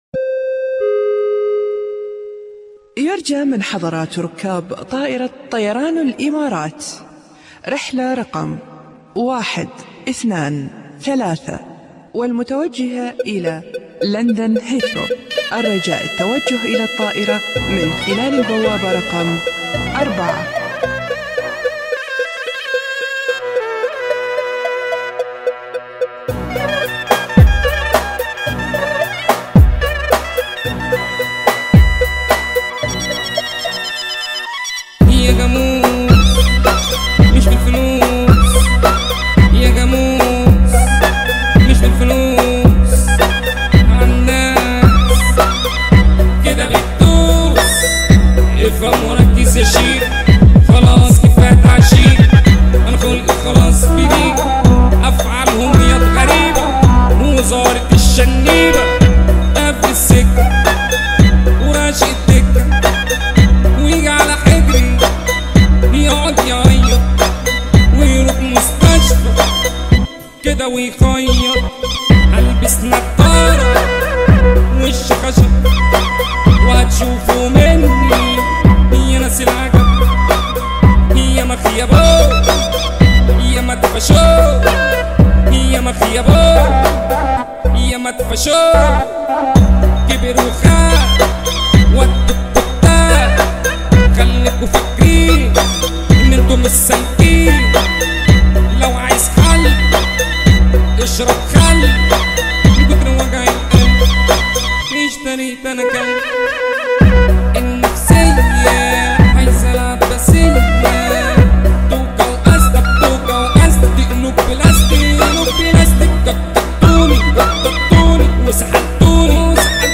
مهرجانات